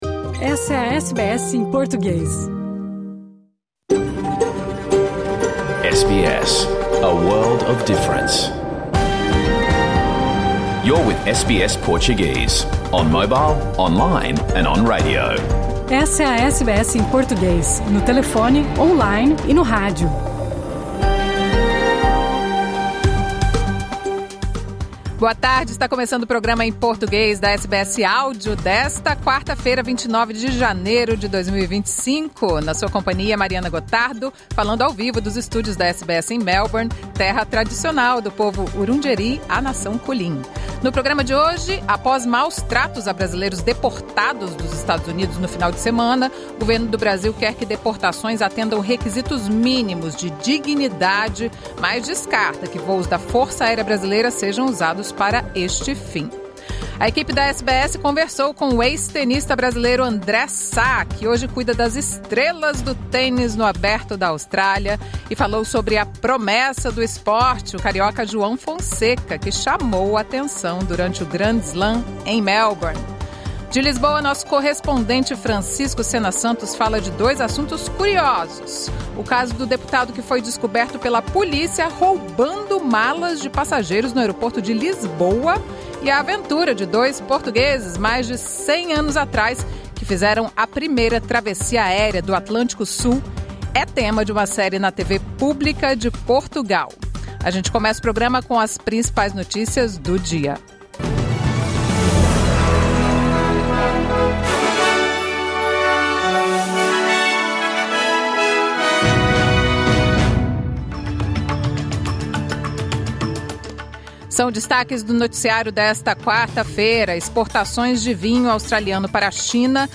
Programa ao vivo | Quarta-feira 29 de Janeiro